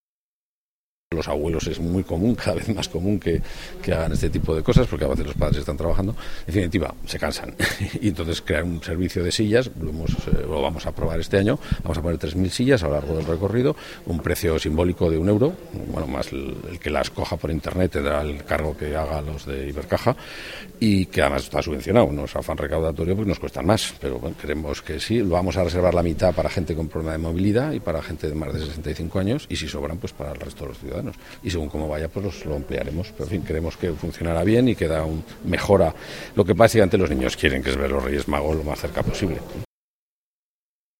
Es por esta razón por la que se reserva el uso de más o menos la mitad de las sillas para mayores y personas con alguna discapacidad. Así lo ha explicado el Consejero de Cultura, Jerónimo Blasco: